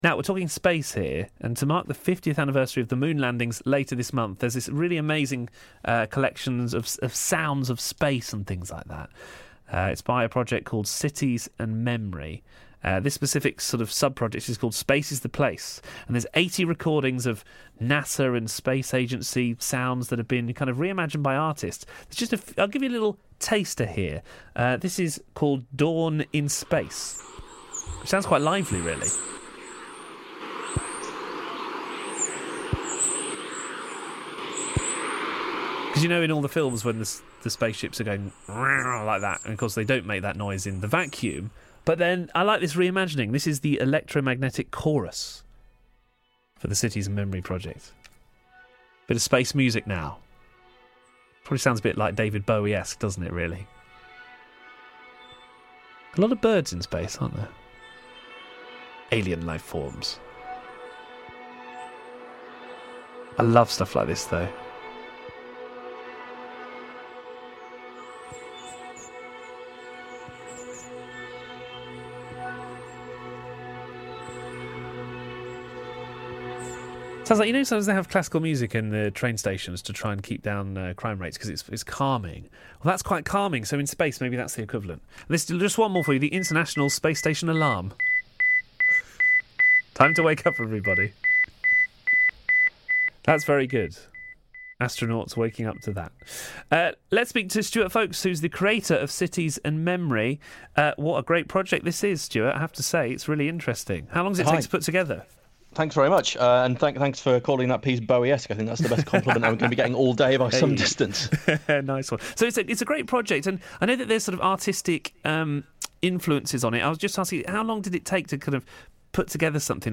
Interview about our Space is the Place project on BBC Radio Northampton, 2 July 2019.